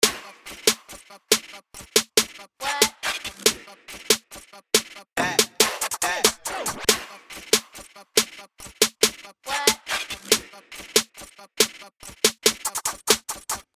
四款高级音色包，共创暗黑风格，专为暗黑街头陷阱音乐和地下节拍打造。
yn_perc_loop_140_bpm.mp3